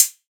SOUTHSIDE_hihat_dirty_metal.wav